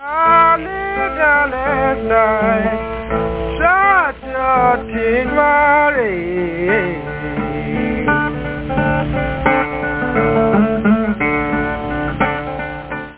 сельского блюза